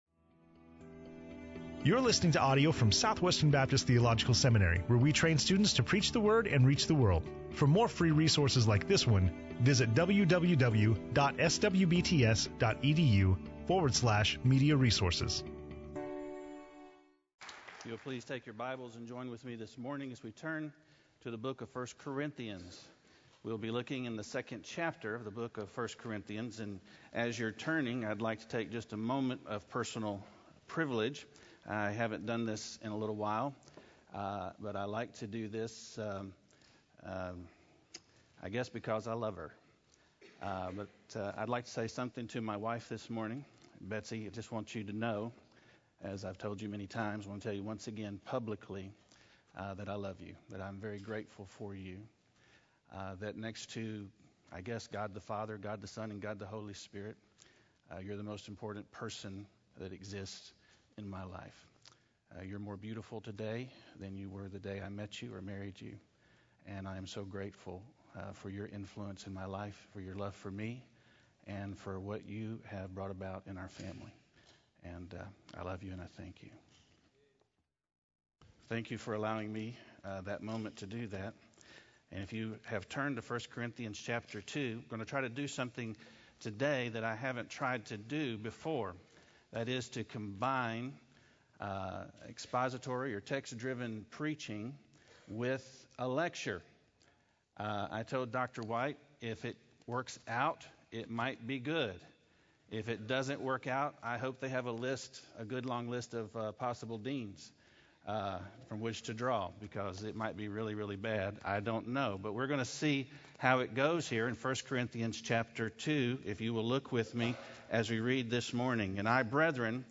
SWBTS Chapel Audio